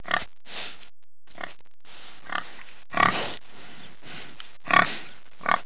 دانلود صدای حیوانات جنگلی 113 از ساعد نیوز با لینک مستقیم و کیفیت بالا
جلوه های صوتی